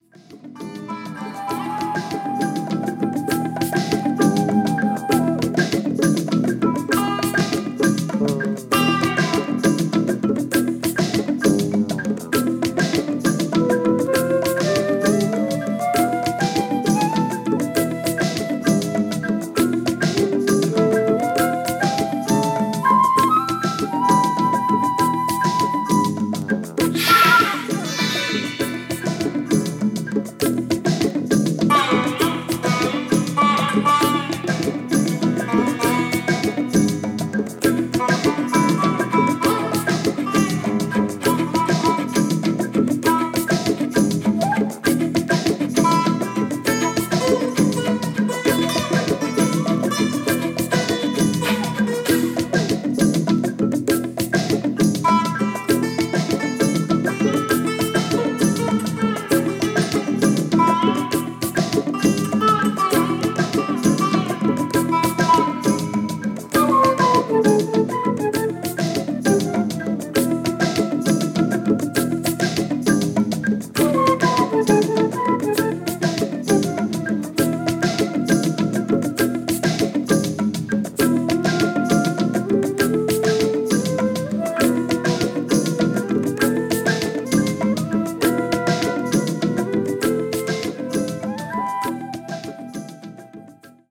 インド・パーカッション奏者